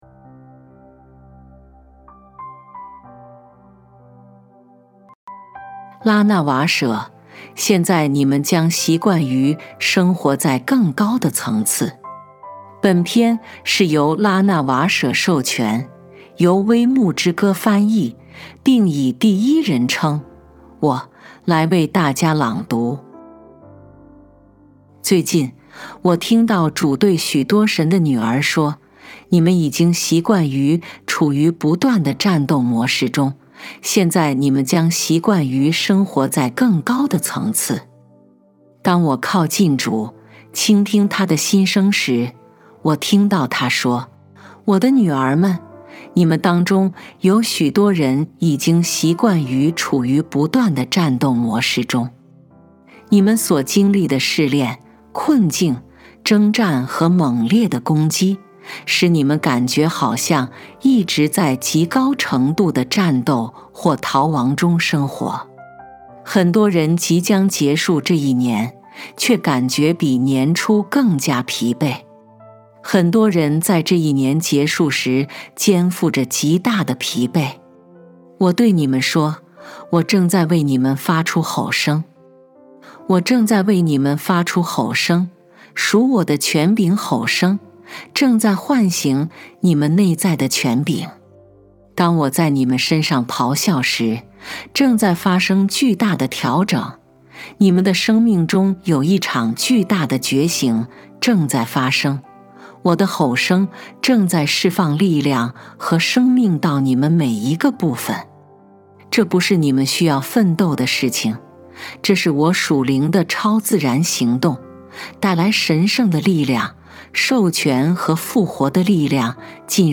本篇是由拉娜瓦舍授权，微牧之歌翻译并以第一人称“我”来为大家朗读。